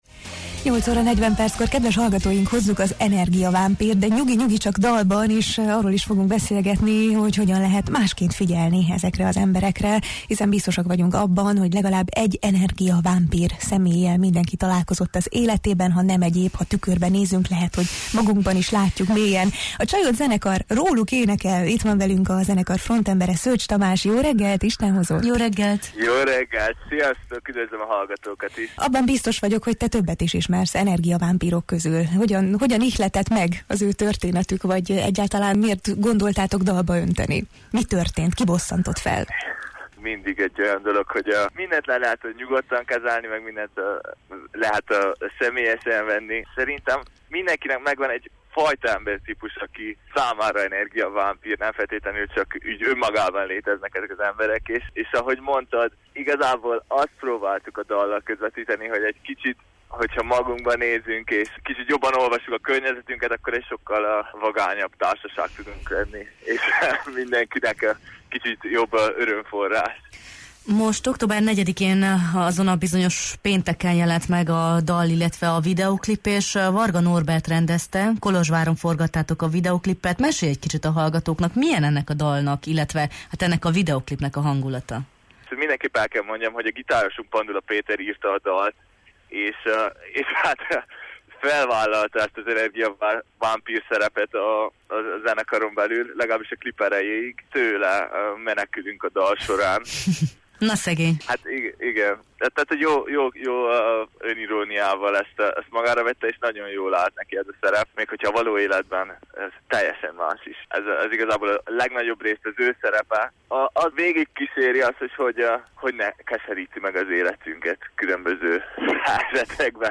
beszélgettünk a Jó reggelt, Erdély!-ben